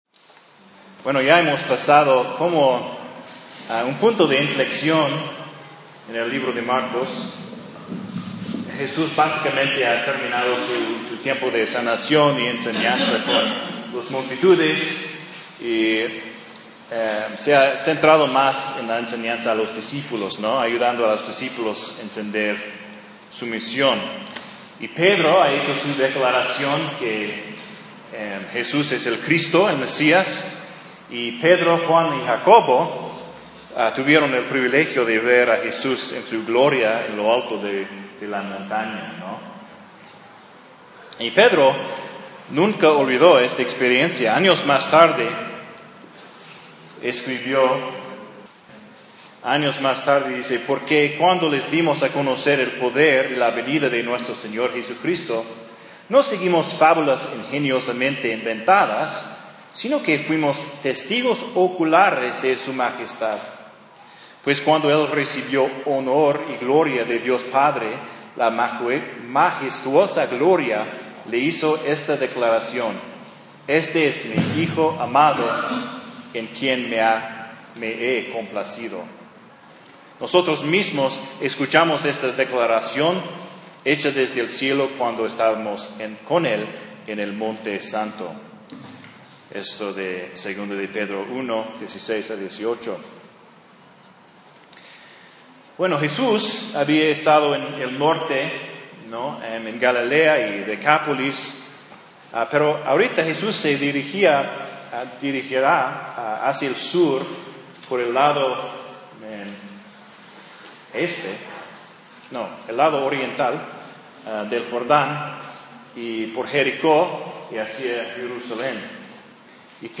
Marcos 9:14-32 (sermón) - En la Biblia
Un sermón de Marcos 9:14-32.